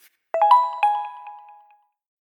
Звуки телефона Lenovo
Chime Lenovo sms